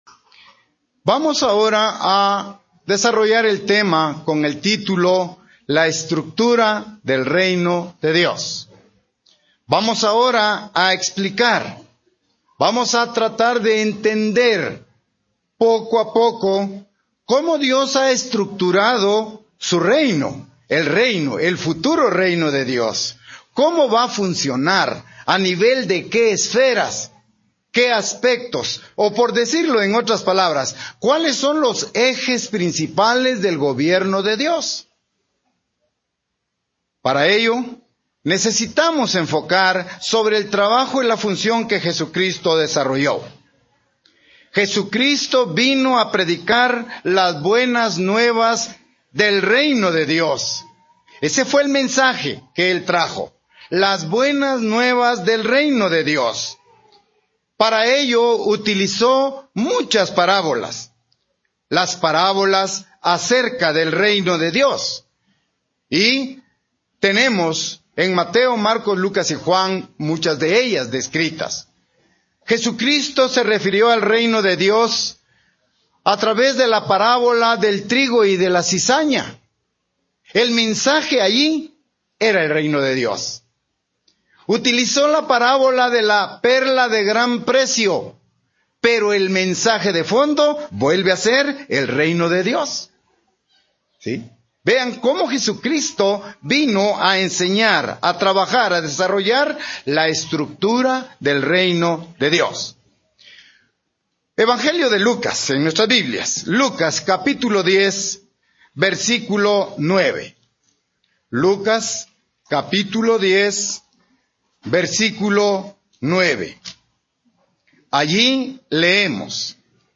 Sermones
Given in Ciudad de Guatemala